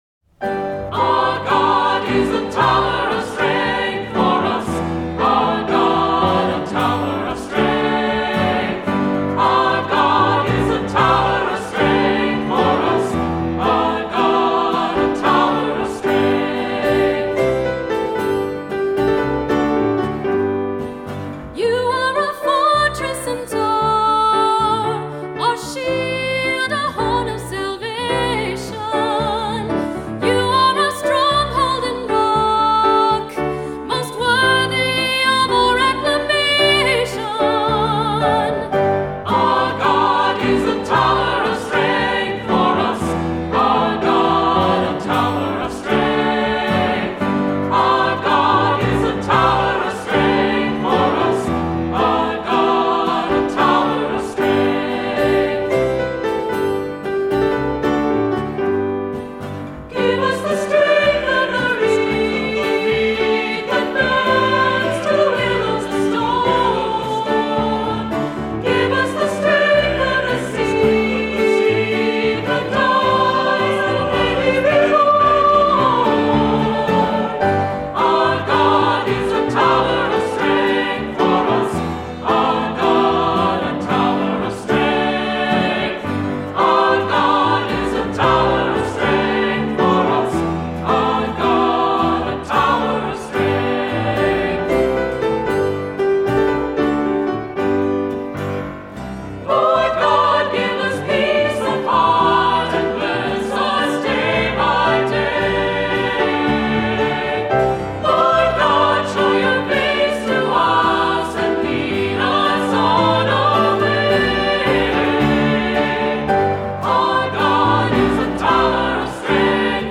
Voicing: SAB, cantor, assembly